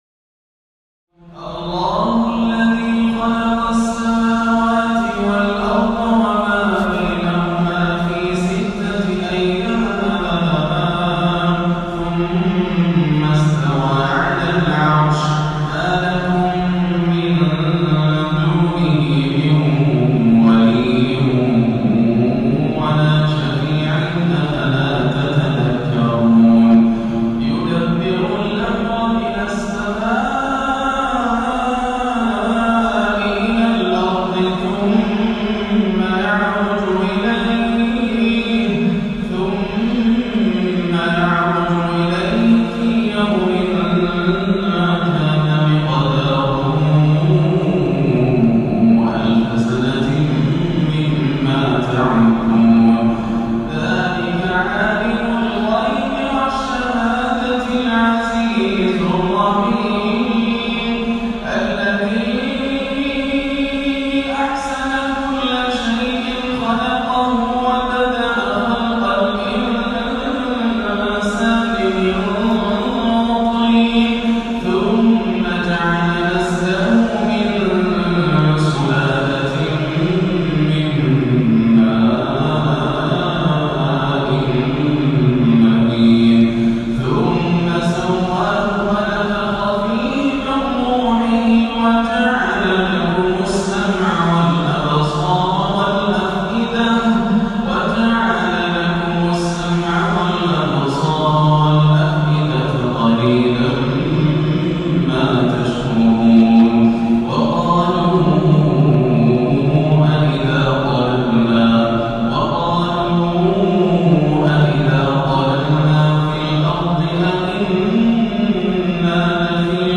فجرية الإبداع والخشوع والإمتاع، عاد بنا الشيخ إلى الأيام الخوالي | سورتي السجدة والانسان | فجر ٢٠ محرم 1438 > عام 1438 > الفروض - تلاوات ياسر الدوسري